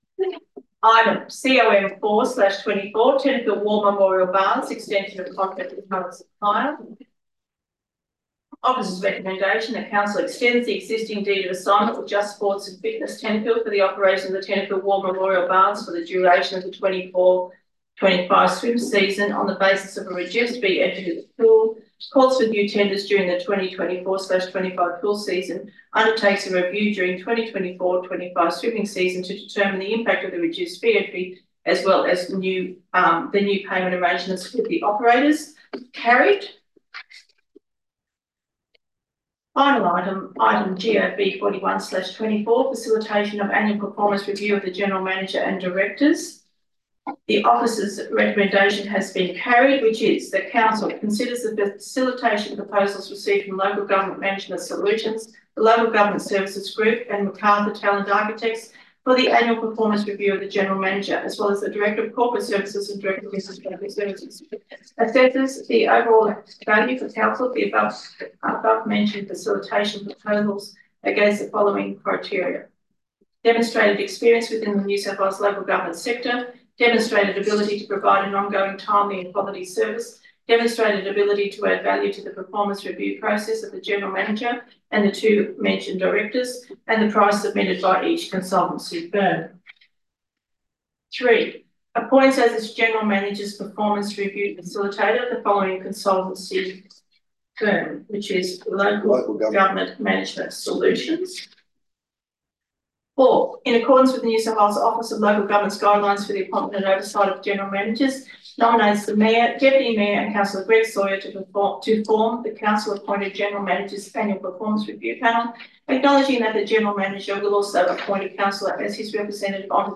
Ordinary Council Meeting 26 June 2024 – Meeting Close (audio only)